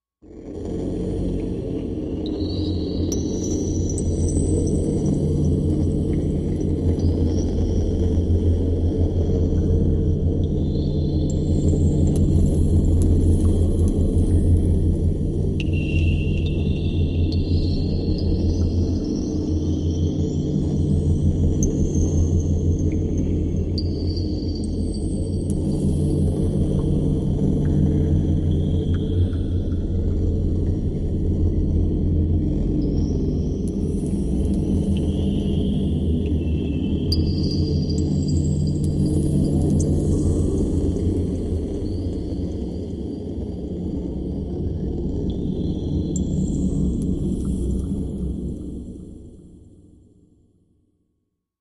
Leaky Reactor High Electric Drips Low Reactor Generator